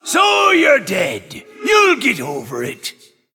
Blackheart is a great announcer.
Blackheart_HeroSlain03.ogg